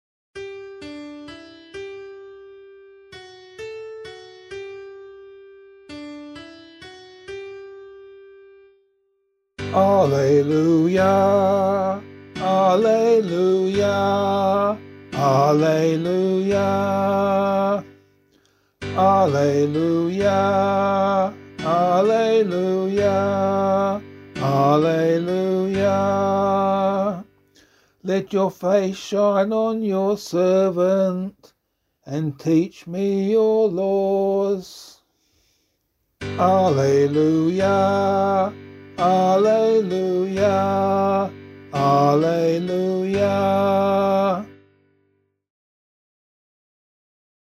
Gospel Acclamation for Australian Catholic liturgy.
vocal